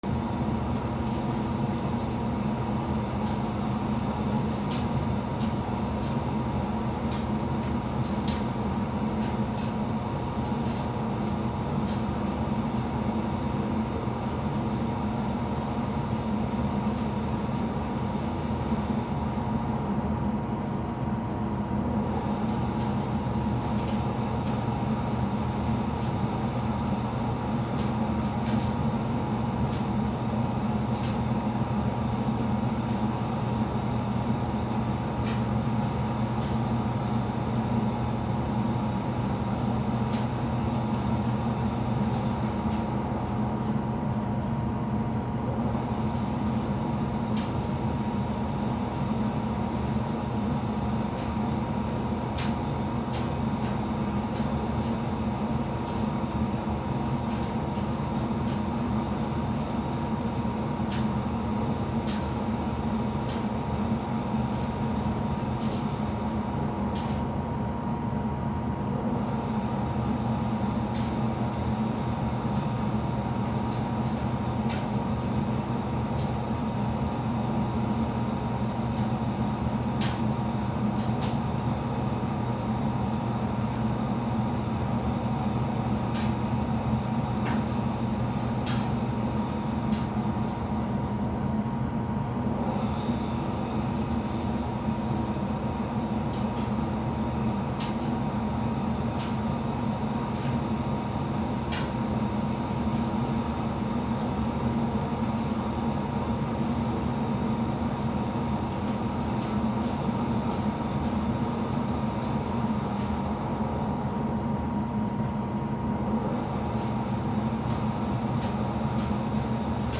乾燥機
/ M｜他分類 / L10 ｜電化製品・機械
コインランドリーD50